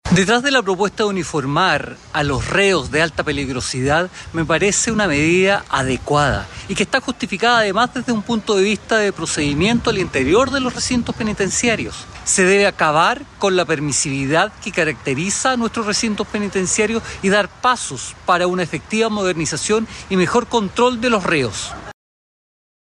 En la misma línea se mostró el diputado del PS, Marcos Ilabaca, quien fue más categórico, al señalar que se debe terminar con la permisividad dentro de las cárceles.